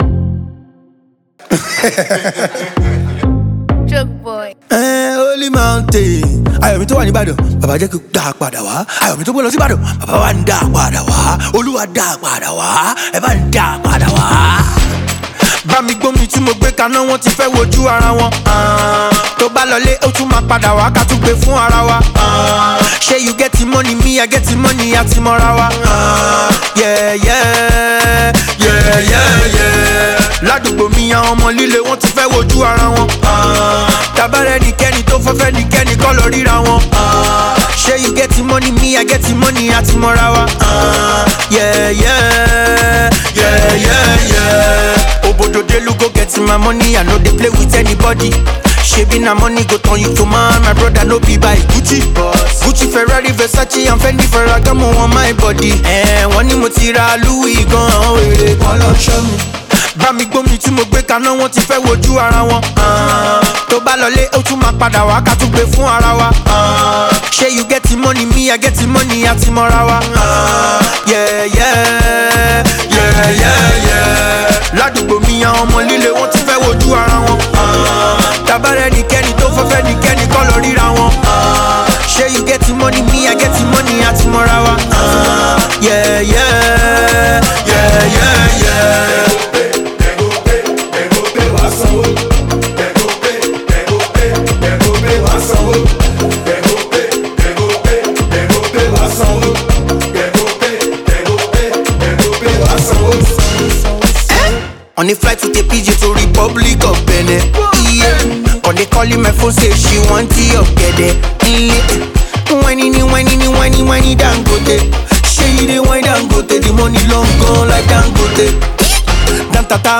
Nigerian vocal powerhouse